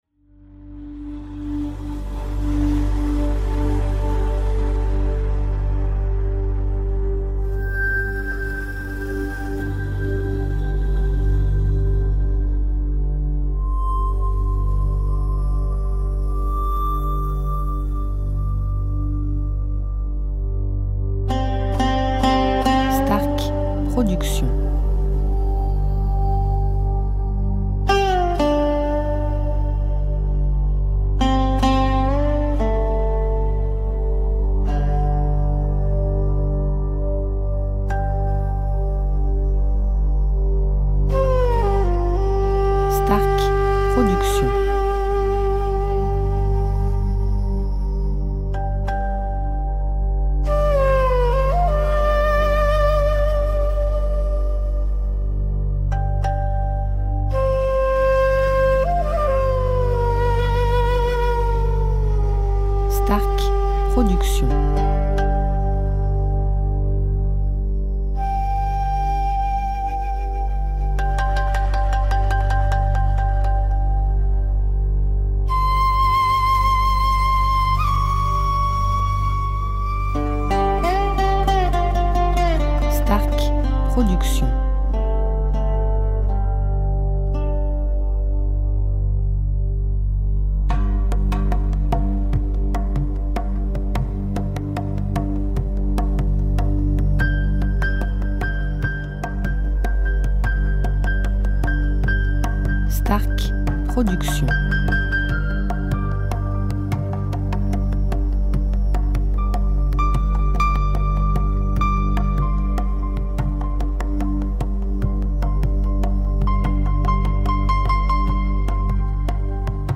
style Ayurvédique durée 1 heure